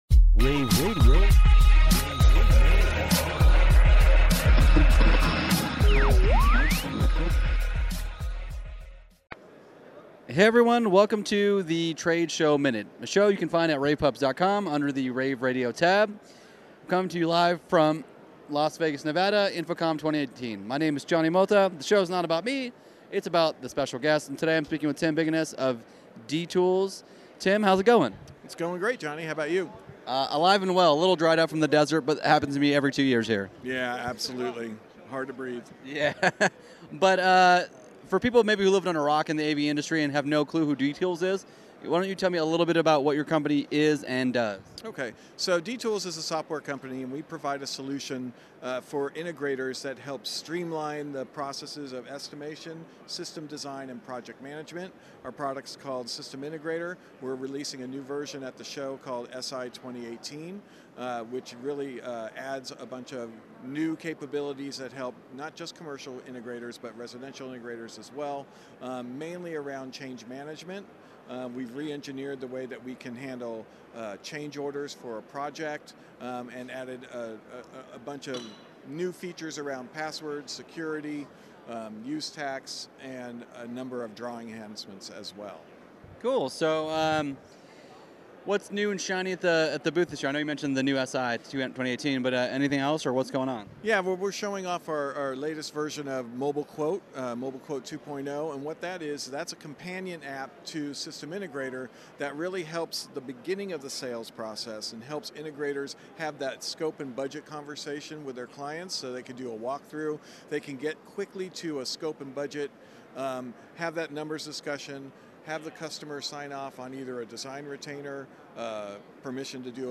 interviews
at InfoComm 2018
InfoComm Day2_showmin-251-2.mp3